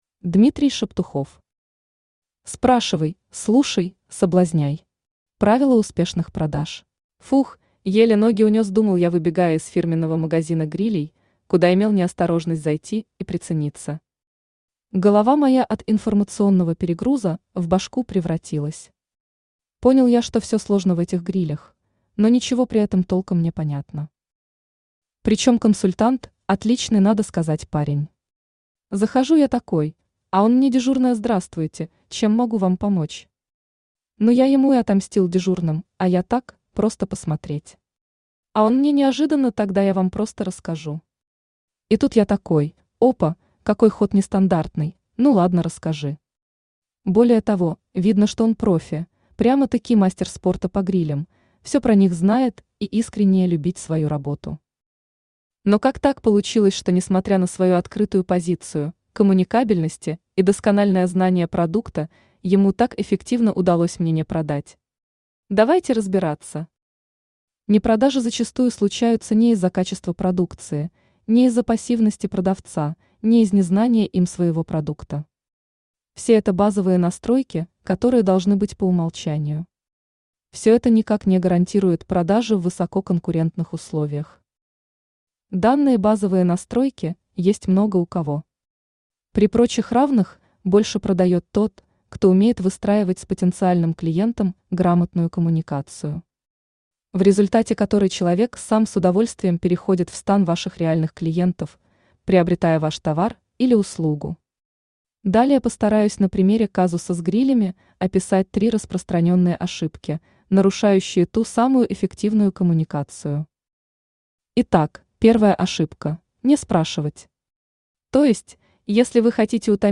Аудиокнига Спрашивай, слушай, соблазняй. Правила успешных продаж | Библиотека аудиокниг
Aудиокнига Спрашивай, слушай, соблазняй. Правила успешных продаж Автор Дмитрий Шептухов Читает аудиокнигу Авточтец ЛитРес.